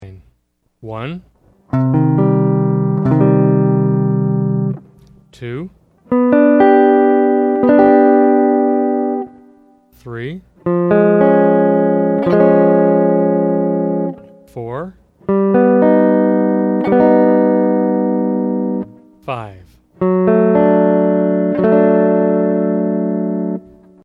Voicing: Guitar w/c